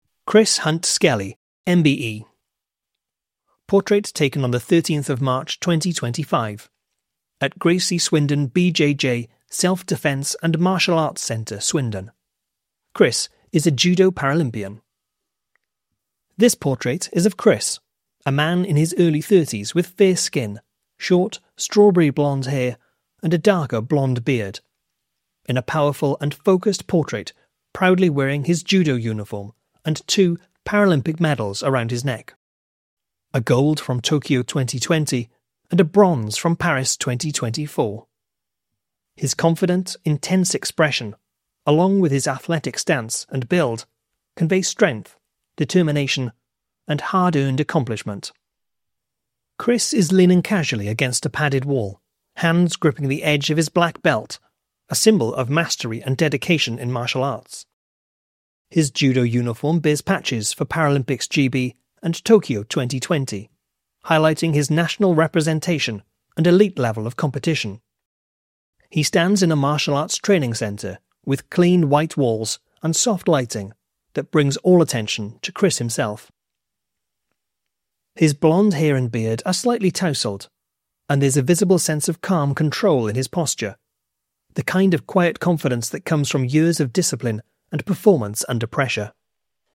Chris Hunt Skelley MBE - Seen Exhibition Audio Description